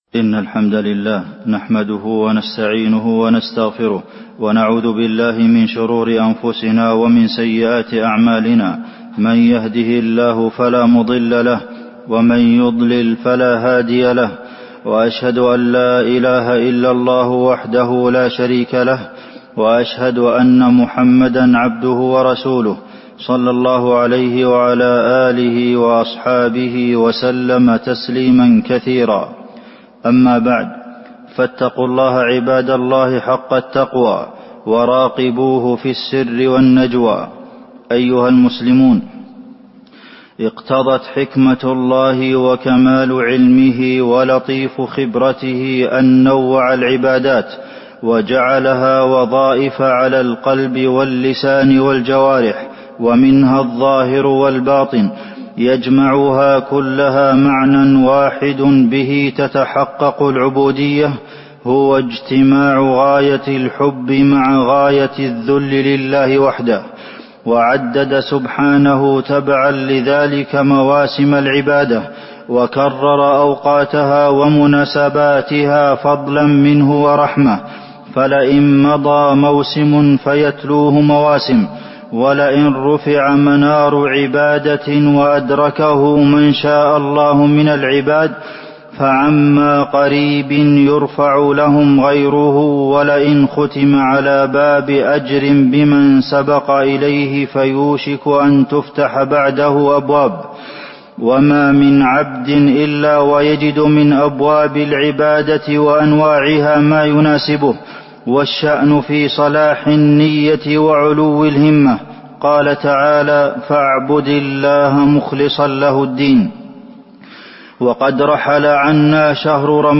تاريخ النشر ٢ شوال ١٤٤٢ هـ المكان: المسجد النبوي الشيخ: فضيلة الشيخ د. عبدالمحسن بن محمد القاسم فضيلة الشيخ د. عبدالمحسن بن محمد القاسم المداومة على العبادة بعد رمضان